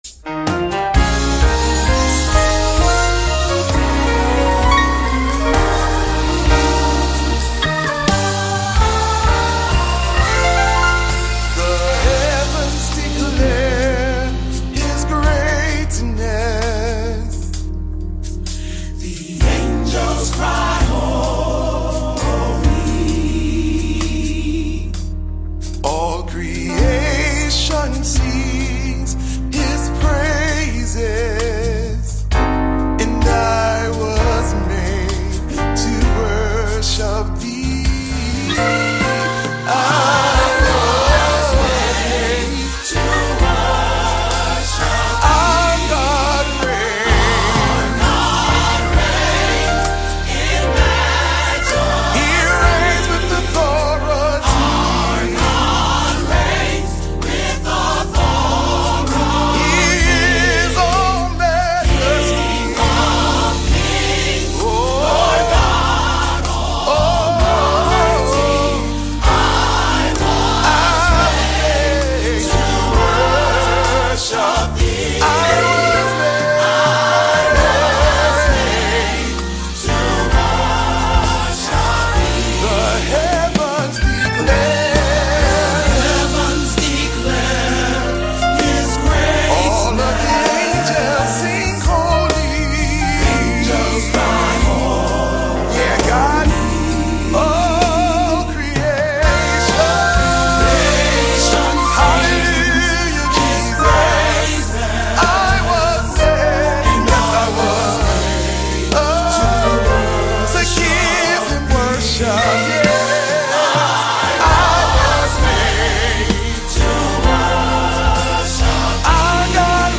Gospel
worshipful and uplifting bonus track